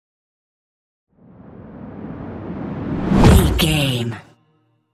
Dramatic whoosh to hit trailer
Sound Effects
Atonal
dark
futuristic
intense
tension